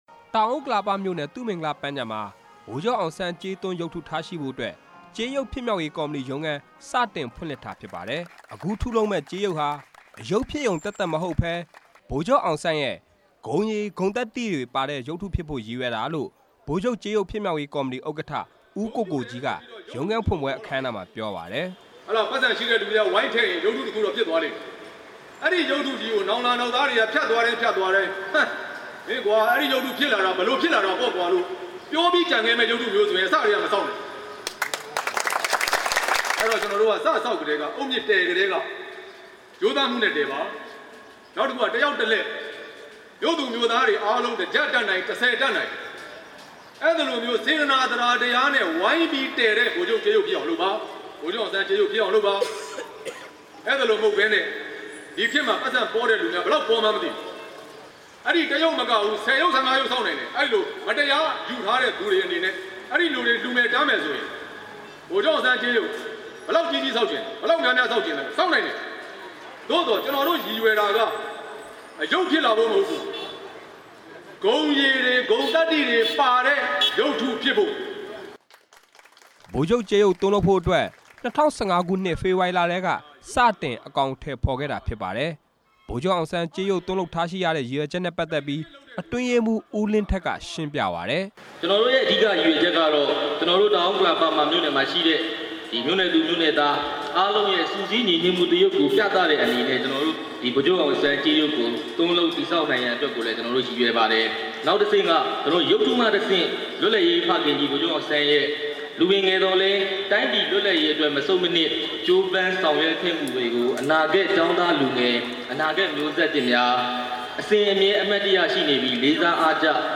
RFA သတင်းထောက်